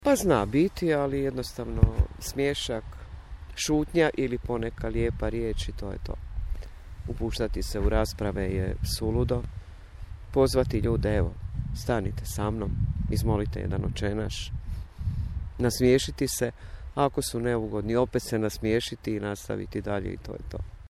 Ni reakcije ni poneki komentari prolaznika ne mogu utjecati na čvrstu odluku da se ustraje u svakodnevnoj molitvi za život, zaključuje naša sugrađanka.